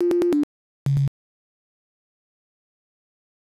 bips_and_clicks